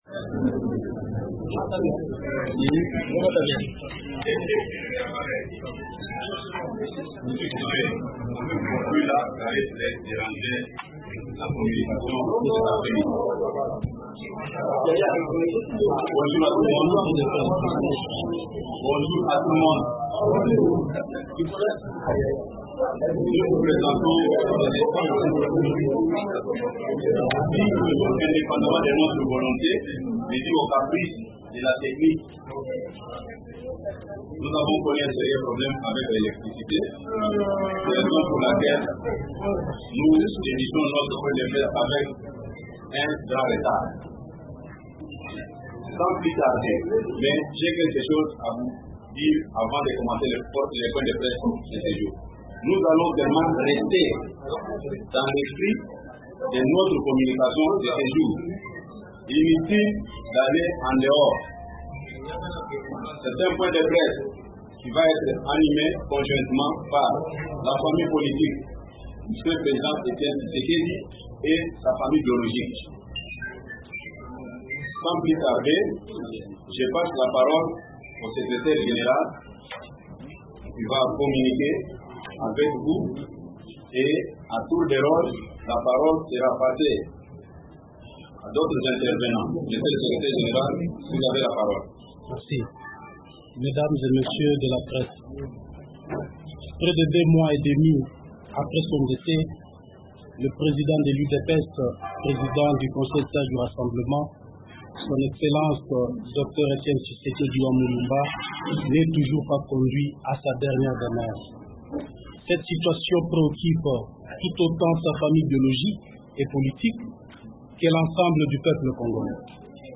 Le corps de l’opposant historique congolais décédé le 1er février sera rapatrié dans la capitale congolaise le 12 mai et enterré au siège de l’Union pour la démocratie et le progrès social (UDPS), a annoncé, lors d’un point de presse, le secrétaire général du parti, Jean-Marc Kabund, samedi à Kinshasa .
Déclaration de Jean-Marc Kabund, secrétaire général de l’UDPS